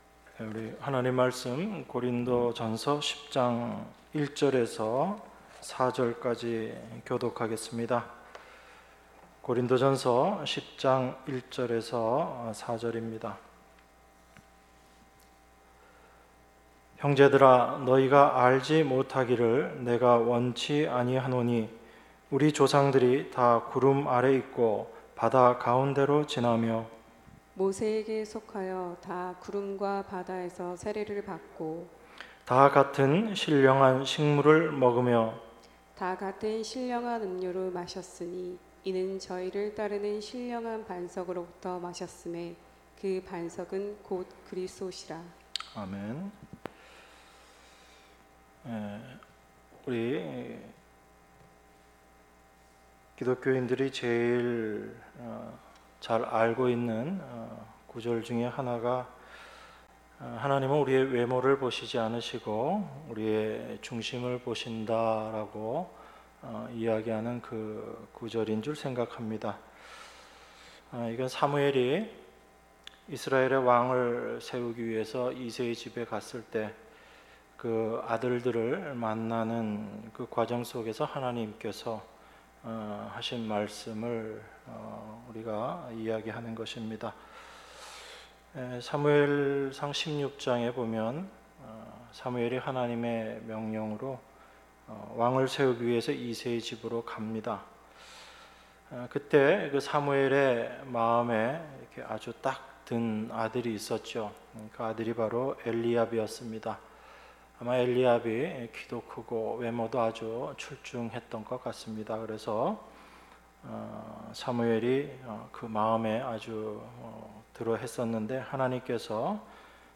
주일1부 고린도전서 10:1~4